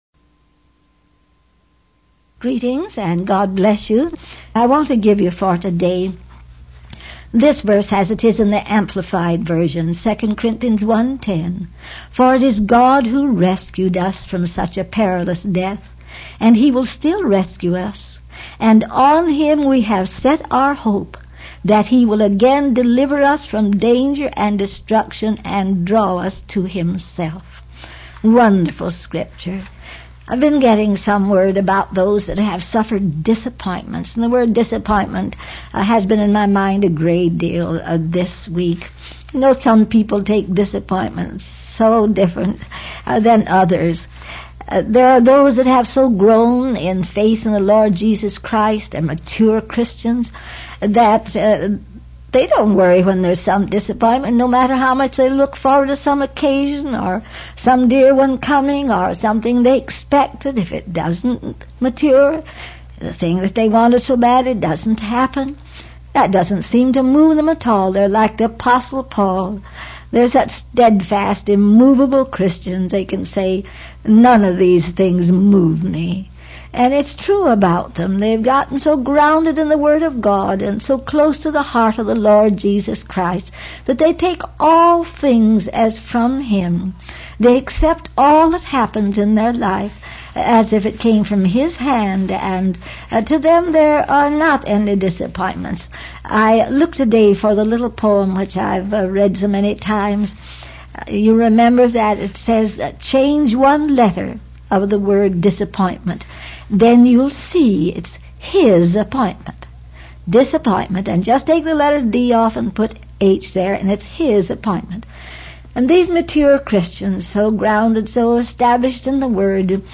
This was a transcript of a Meditation Moments #76 broadcast.